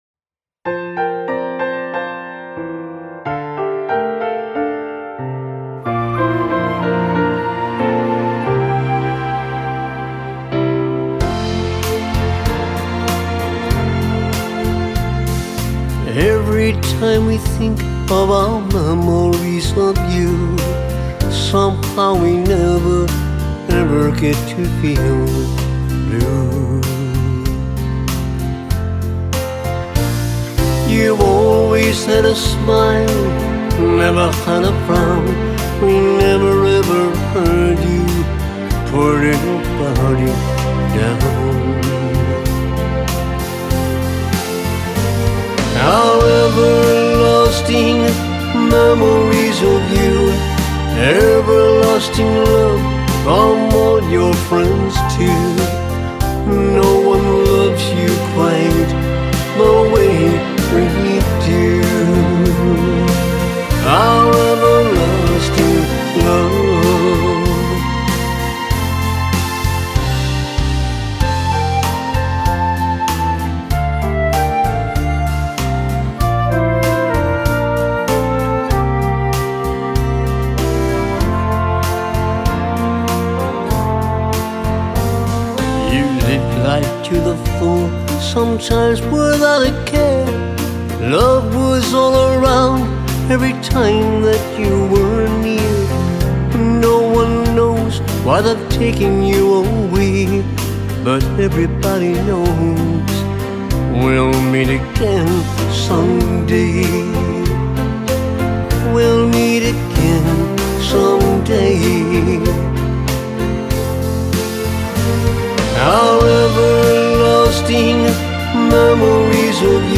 Country Singer